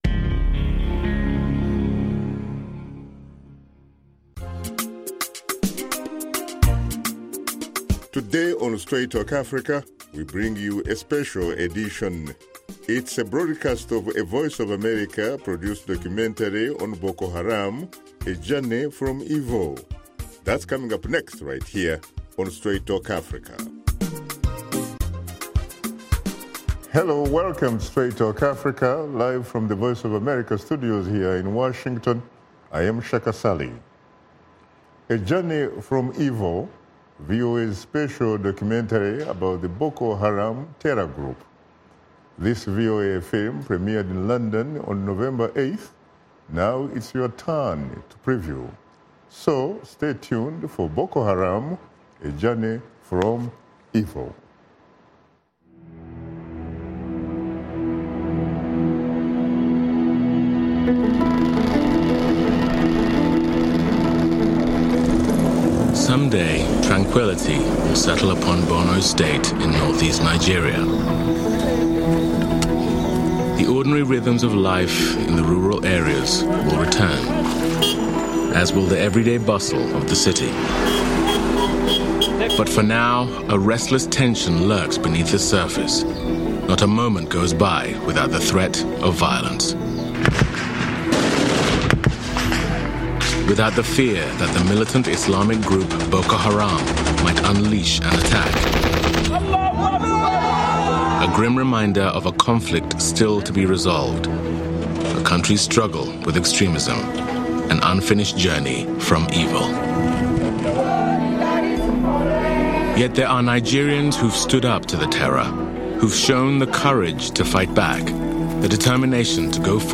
“A Journey from Evil,” VOA’s special documentary about the Boko Haram terror group.